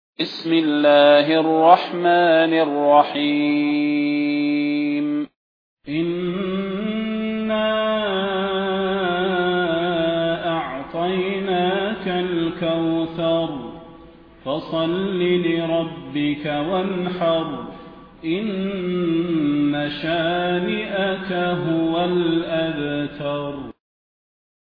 المكان: المسجد النبوي الشيخ: فضيلة الشيخ د. صلاح بن محمد البدير فضيلة الشيخ د. صلاح بن محمد البدير الكوثر